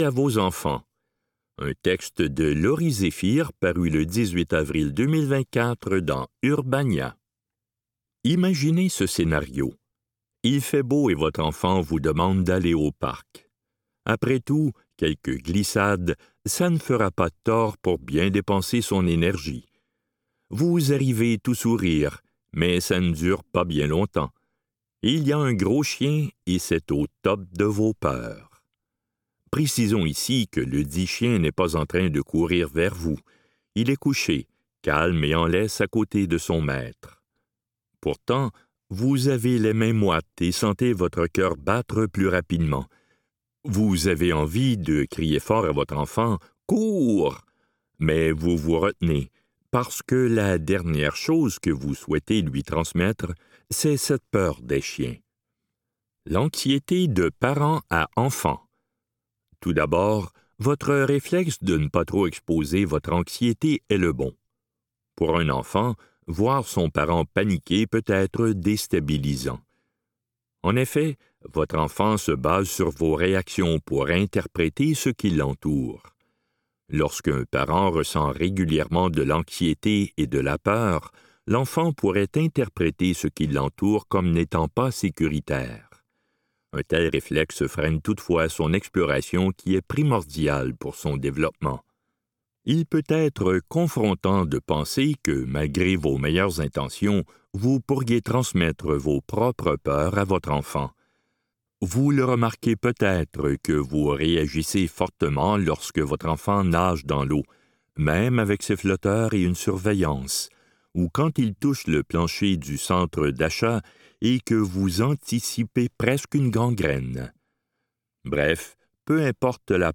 Dans cet épisode de On lit pour vous, nous vous offrons une sélection de textes tirés des médias suivants : Urbania, Le Devoir et La Presse.